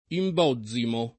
DOP: Dizionario di Ortografia e Pronunzia della lingua italiana
imbozzimo [ i mb 0zz imo ]